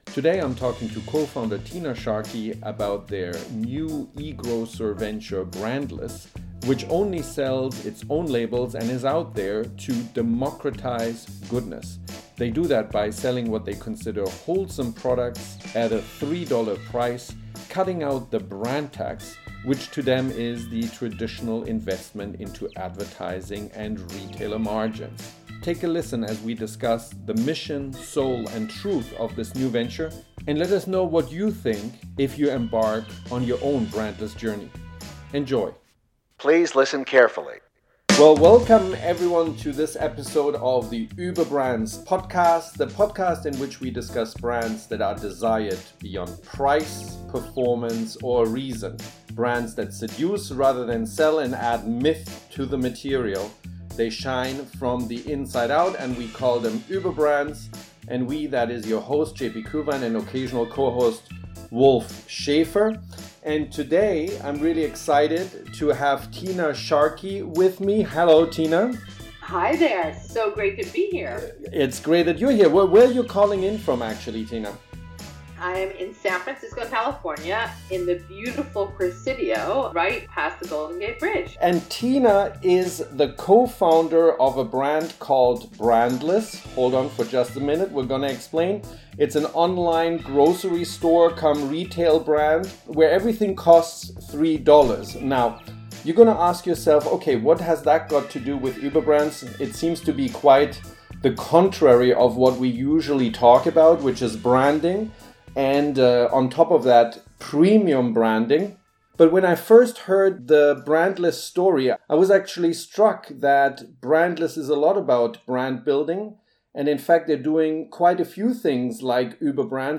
A Brand Built On ‘Less’? – Interview